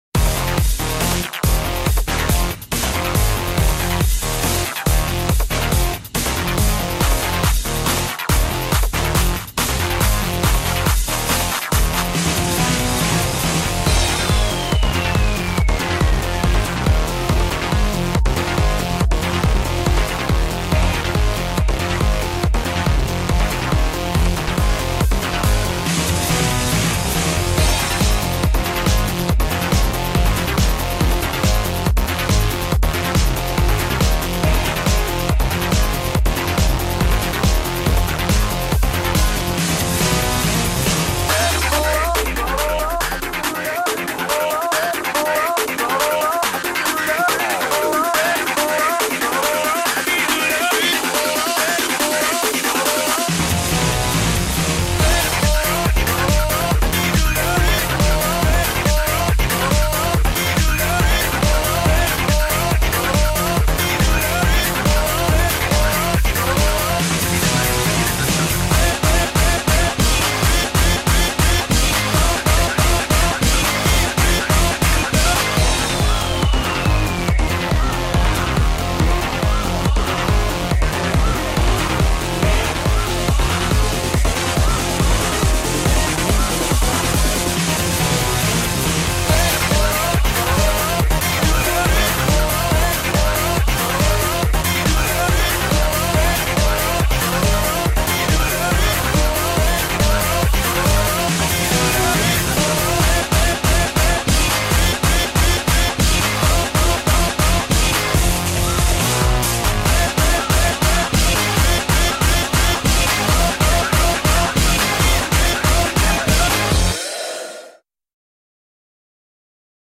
Audio QualityPerfect (Low Quality)